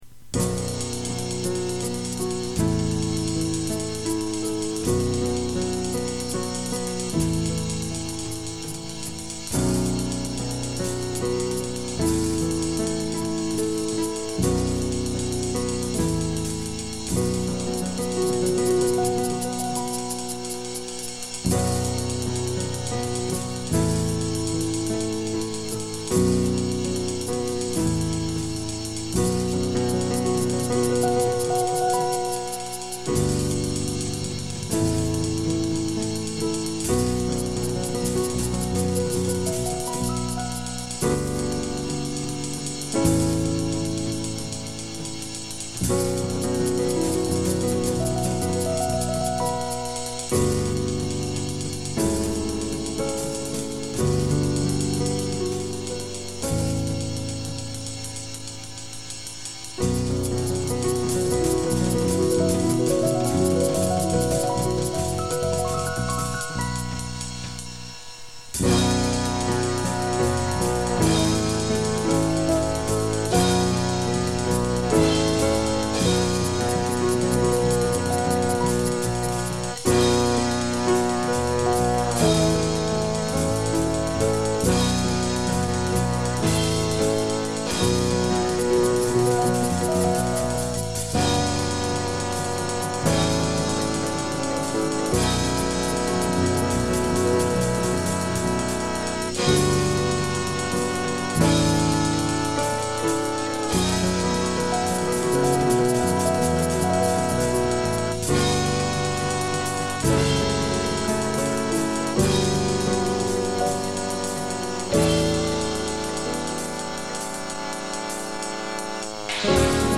MORE TO PLAY-ALONG WITH